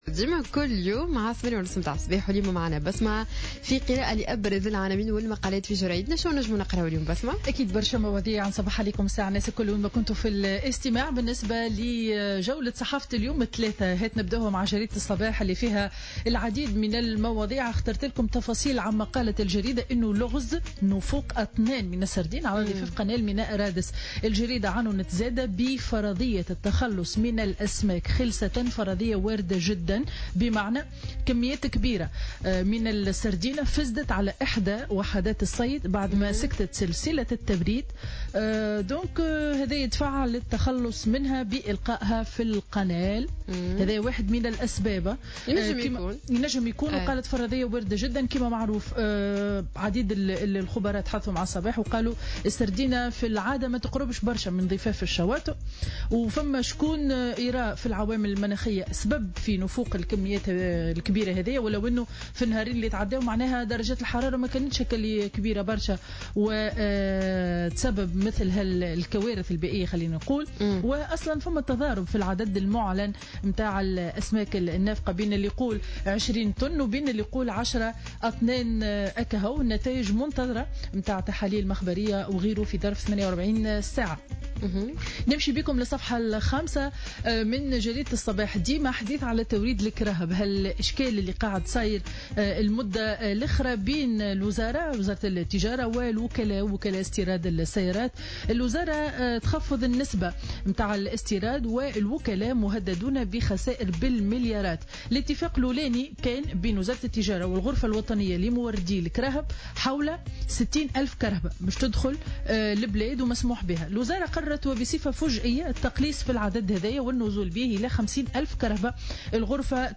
Revue de presse du Mardi 08 septembre 2015